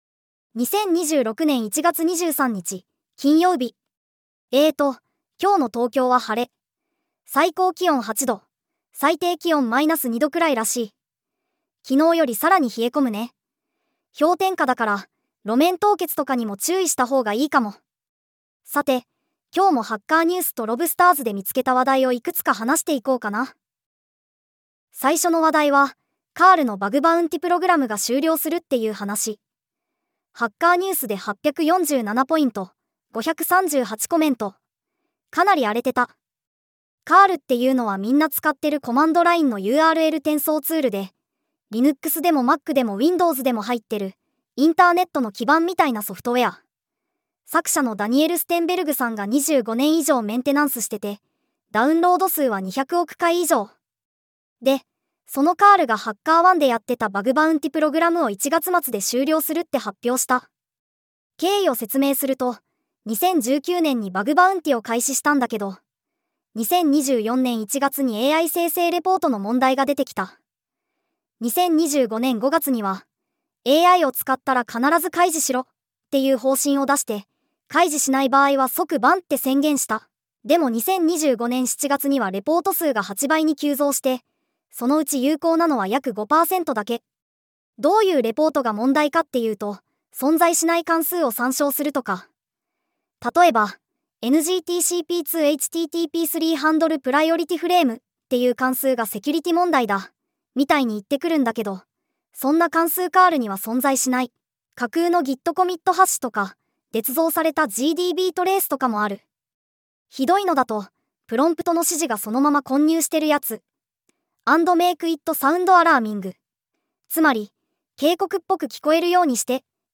テトさんに技術系ポッドキャストを読んでもらうだけ
音声: VOICEPEAK 重音テト キャラクター